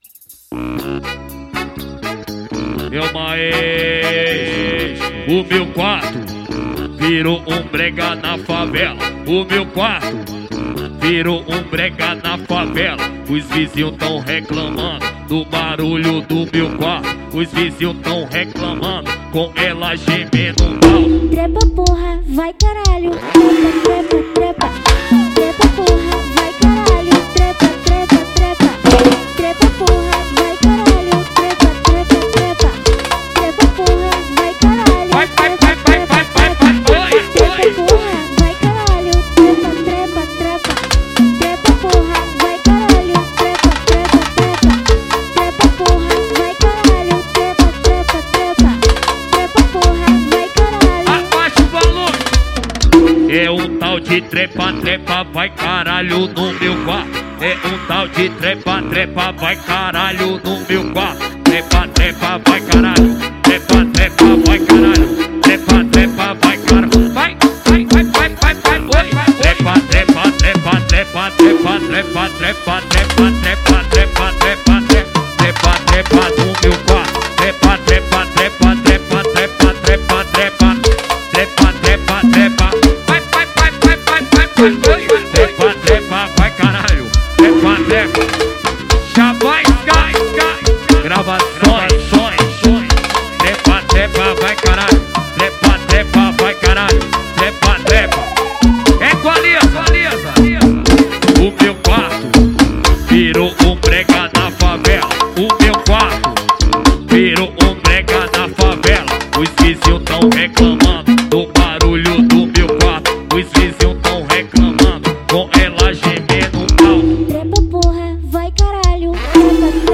2024-06-16 20:14:57 Gênero: MPB Views